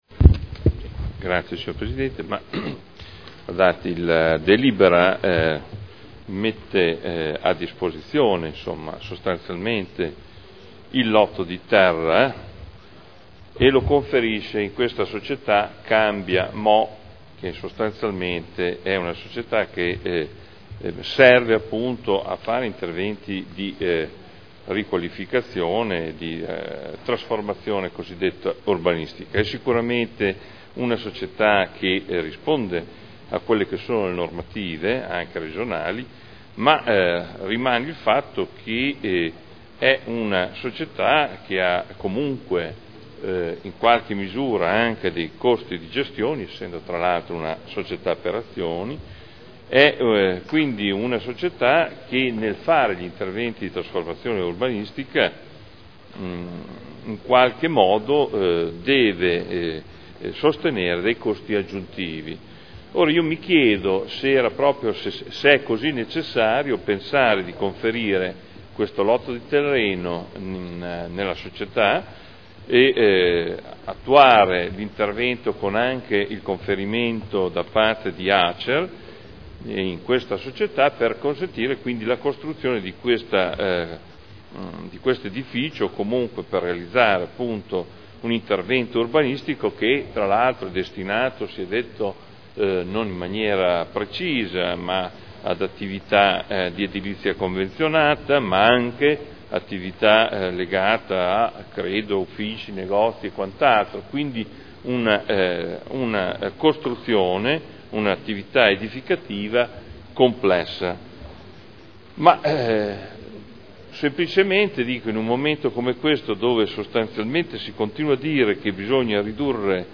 Seduta del 22/12/2011. Dibattito su proposta di deliberazione. Conferimento alla Società di Trasformazione Urbana CambiaMo S.p.A. di lotto edificabile presso il comparto Ex Mercato Bestiame – Approvazione